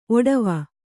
♪ oḍava